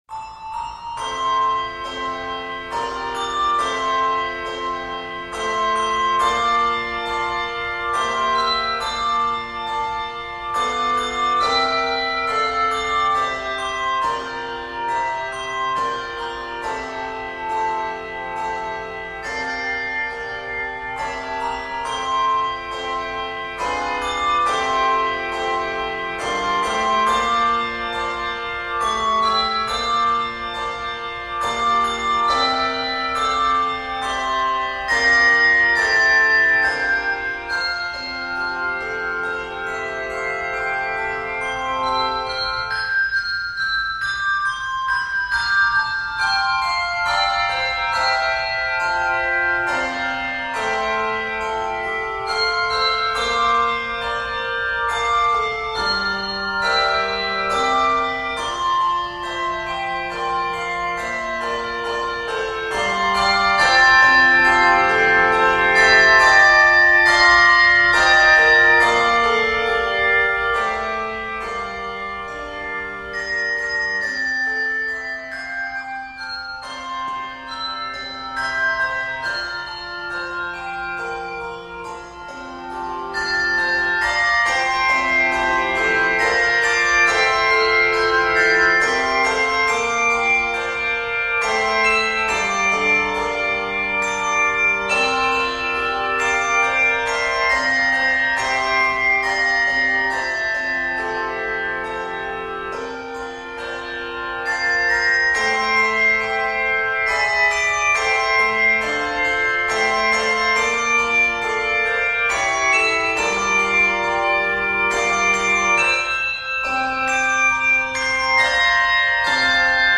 Warm and embracing harmonies